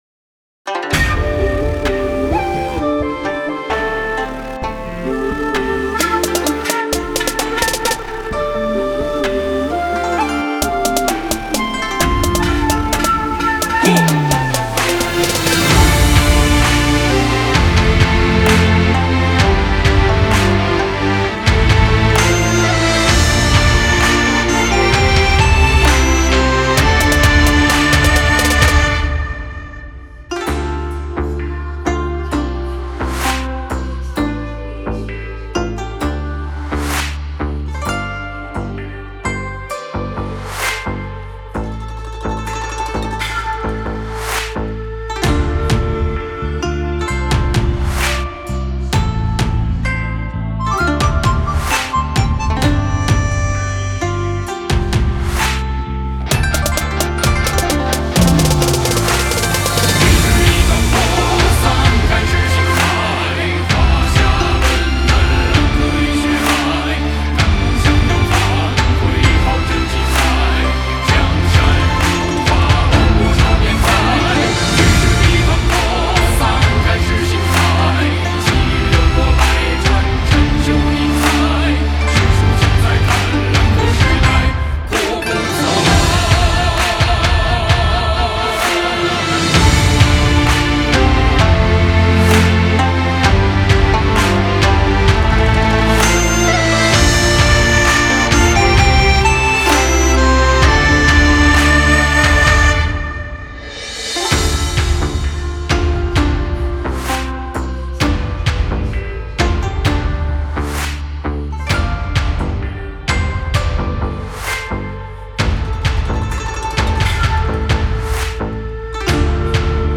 校歌伴奏
xiaogebanzou.mp3